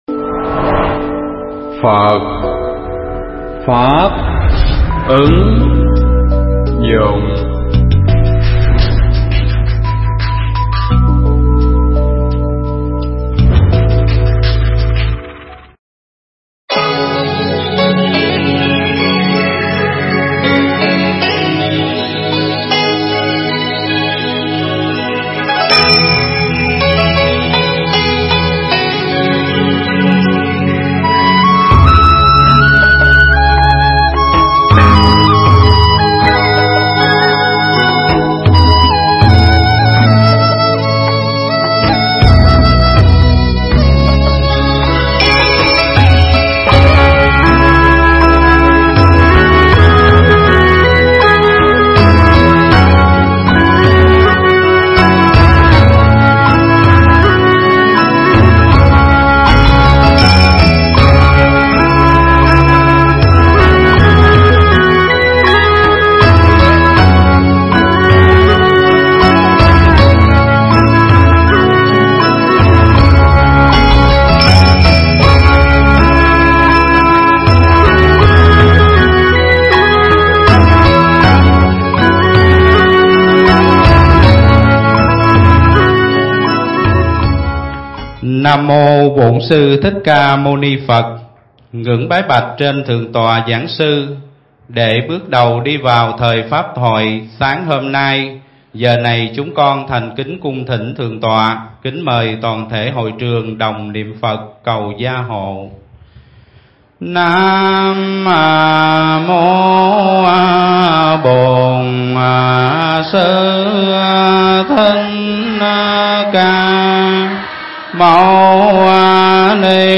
Nghe Mp3 thuyết pháp Công Đức Pháp Thí (KT30)